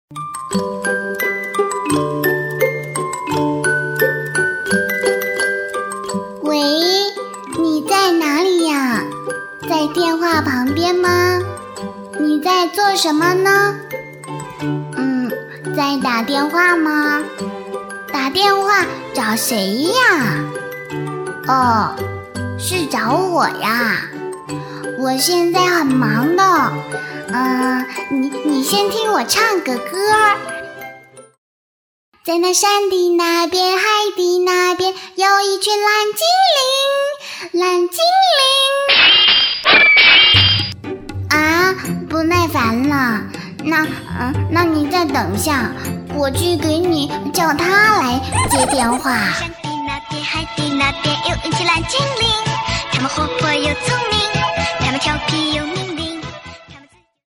女声配音
彩铃女国34